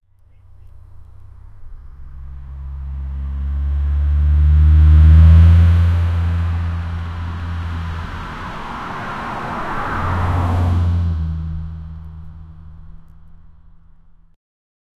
the-sound-of-the-mclaren-in6tofek.wav